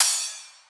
Key-rythm_cymbal_01.wav